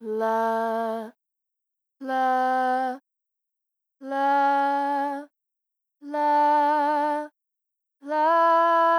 (fs, x) = read('../audio/femaleSingingScale.wav')
plt.title('Voice Singing')